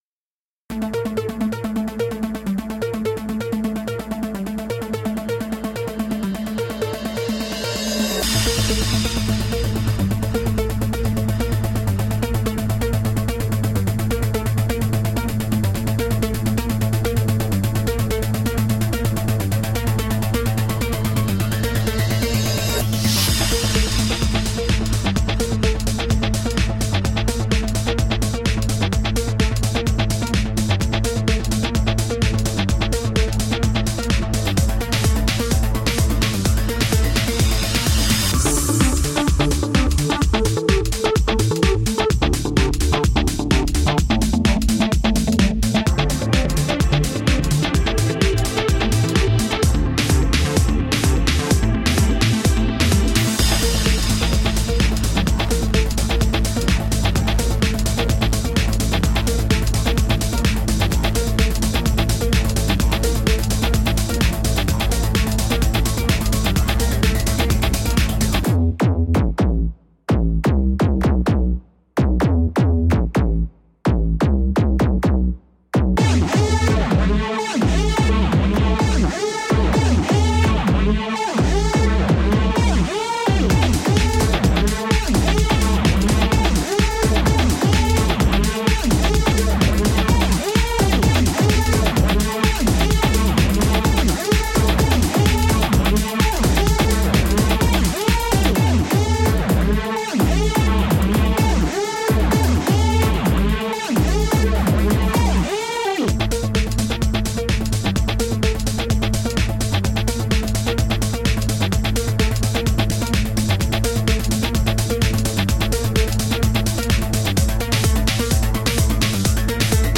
Electronica with a heart.
Tagged as: Electronica, Techno, Hard Electronic, Industrial